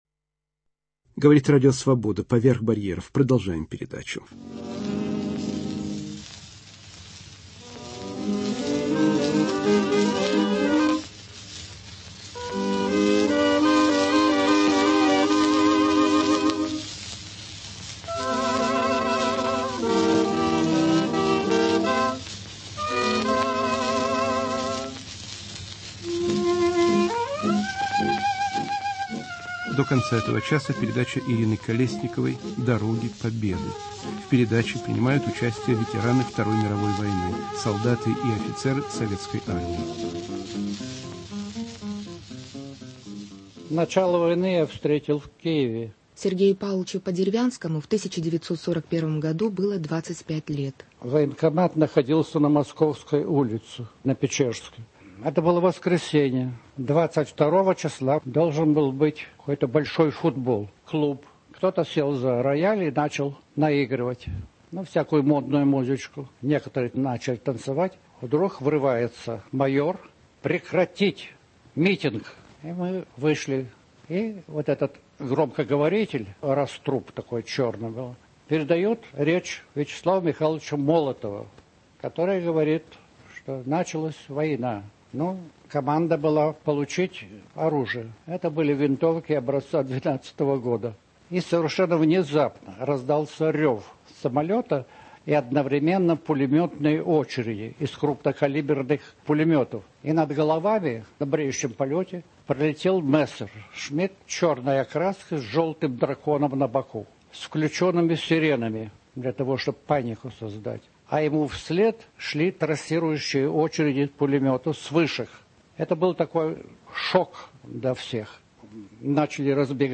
"Дороги войны". Вспоминают бывшие советские военнослужащие - ветераны Второй мировой войны